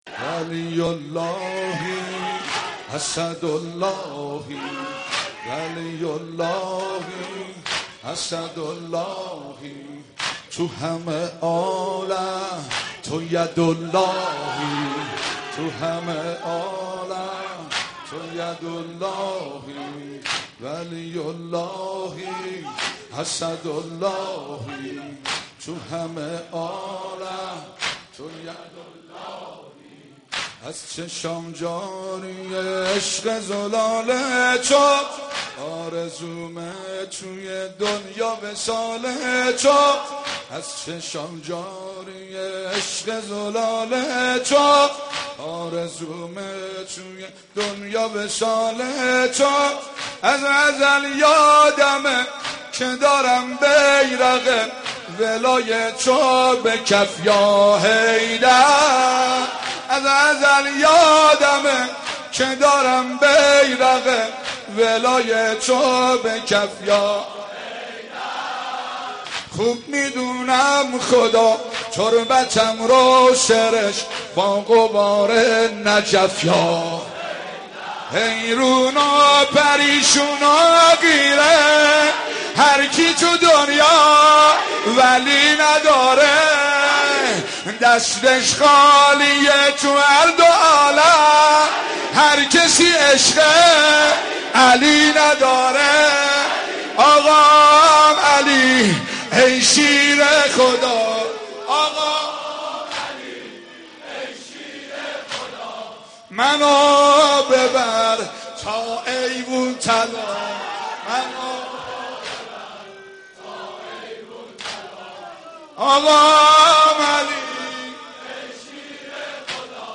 رمضان 89 - سینه زنی 2
رمضان 89 - سینه زنی 2 خطیب: حاج مهدی سلحشور مدت زمان: 00:04:20